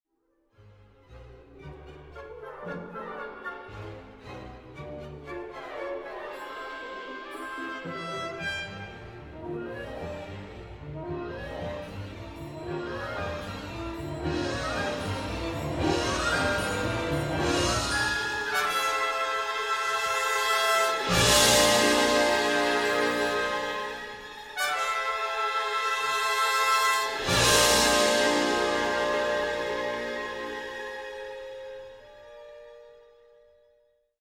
Gra fal jest bardzo satysfakcjonująca – lekka, zwiewna i barwna. Także tu dużo zmian agogicznych, zwolnień i przyspieszeń, ale wszystkie one jakoś pasują do charakteru muzyki:
Valery Gergiev, London Symphony Orchestra, 2009, 25:30, LSO Live